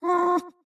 Minecraft Version Minecraft Version 25w18a Latest Release | Latest Snapshot 25w18a / assets / minecraft / sounds / mob / happy_ghast / hurt1.ogg Compare With Compare With Latest Release | Latest Snapshot
hurt1.ogg